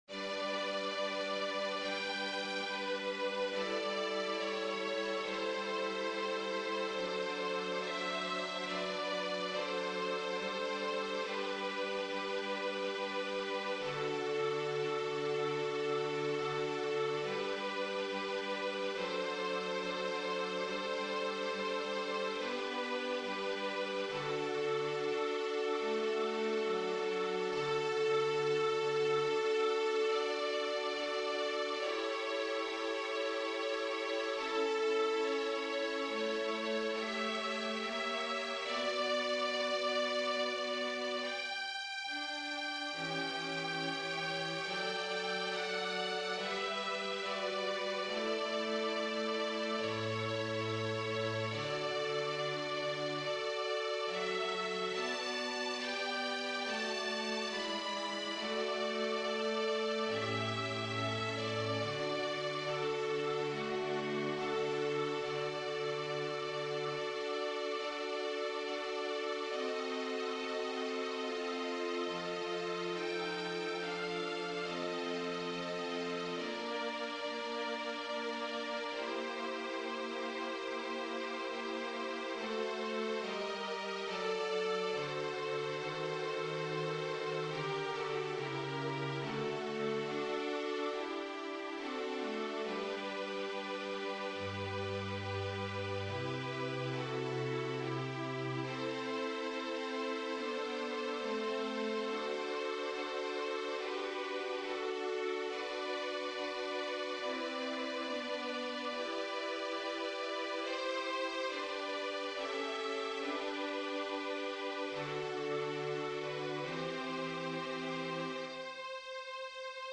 みんなで補い合いながらアンサンブルをしています。
川崎・大田区　弦楽合奏団　フルートアンサンブル　シニア合奏団　多摩川ストリングス　芝田祥子ヴァイオリン教室